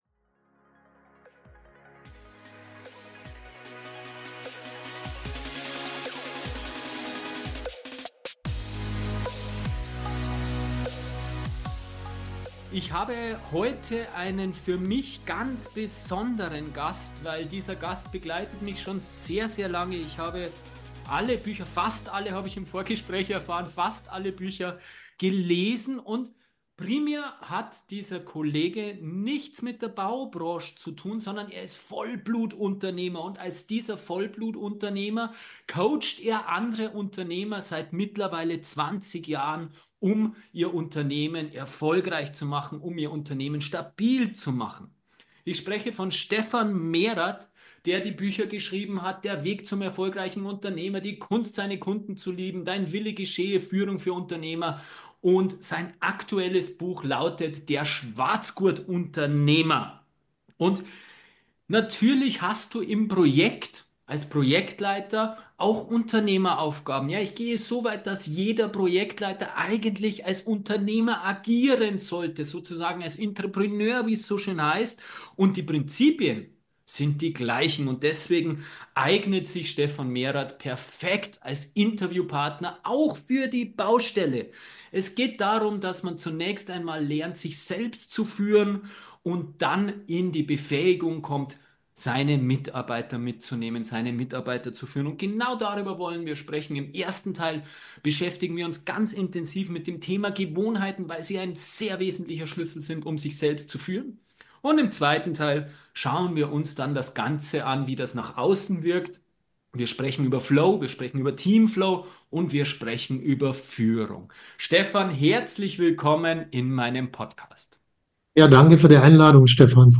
Gespräch